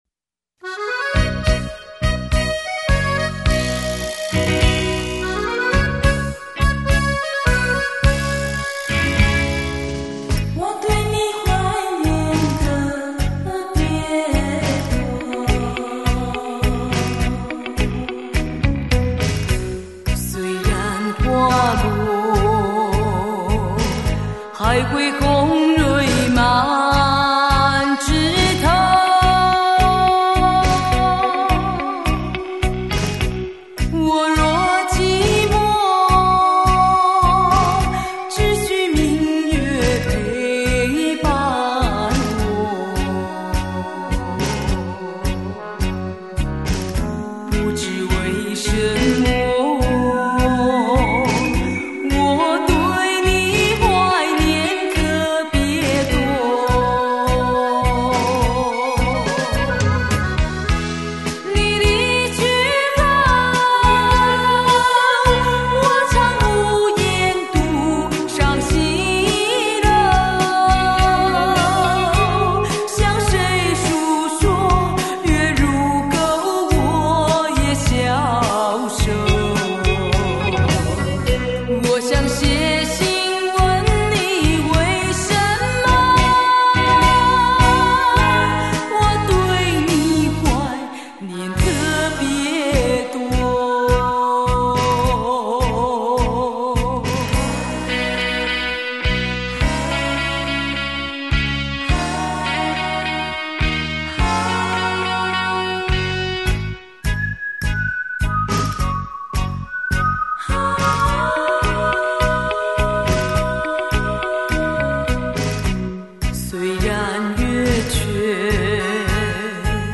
动听的歌声， 如梦般的永恒的旋律